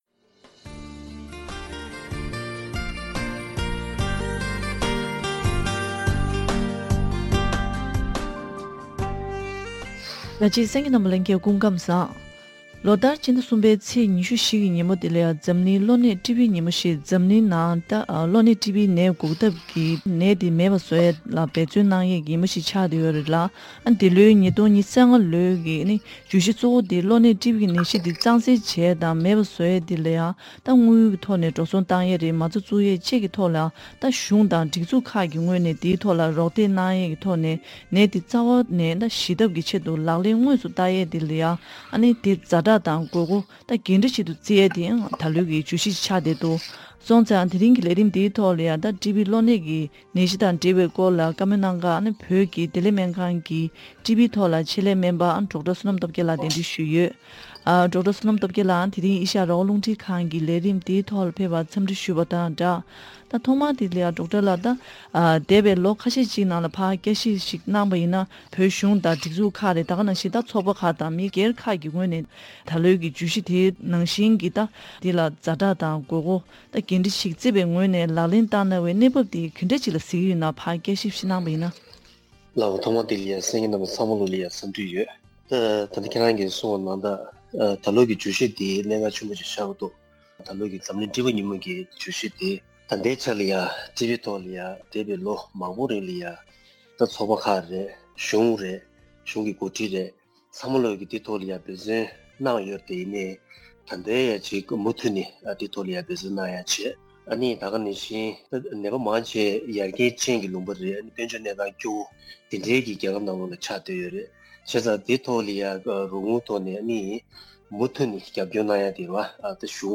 བཀའ་དྲི་ཞུས་པའི་ལས་རིམ་ཞིག་གསན་རོགས་གནང་།།